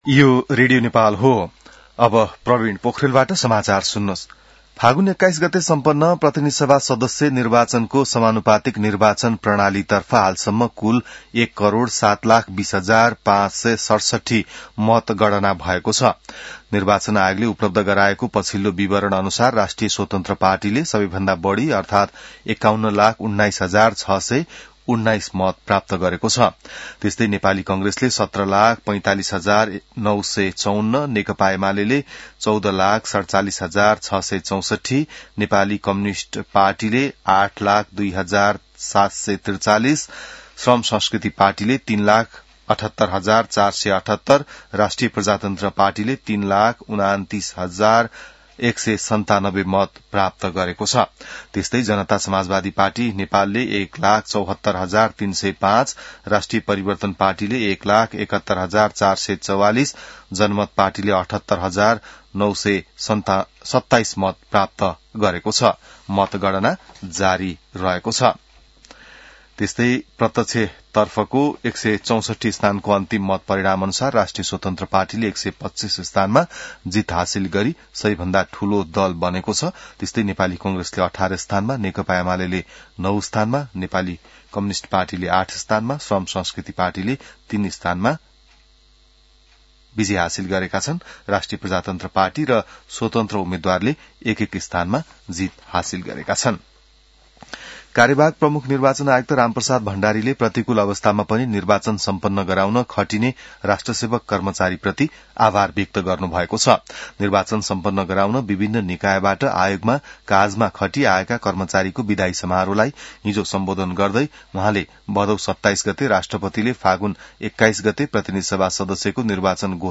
बिहान ६ बजेको नेपाली समाचार : २७ फागुन , २०८२